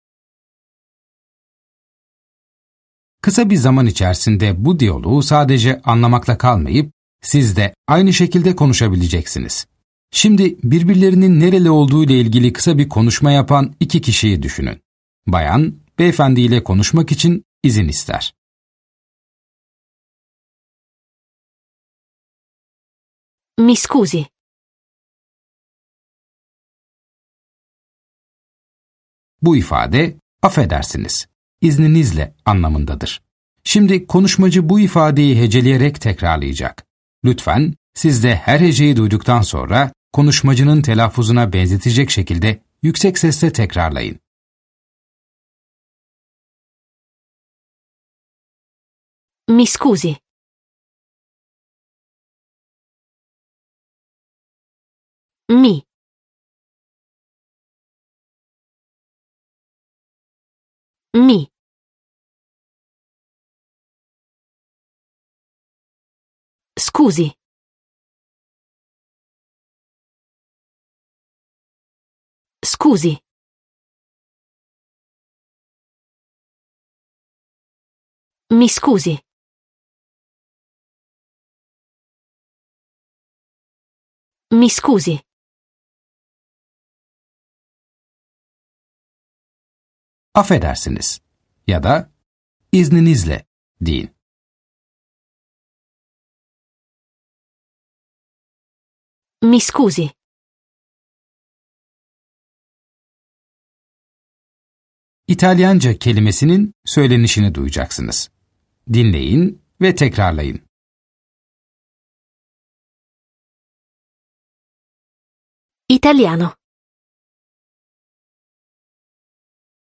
Dersler boyunca sizi yönlendiren bir kişisel eğitmeniniz olacak. Ana dili İtalyanca olan iki kişi de sürekli diyalog halinde olacaklar. Yönlendirmeniniz size söz verdikçe gerekli tekrarları yapın ve sorulan sorulara cevap verin.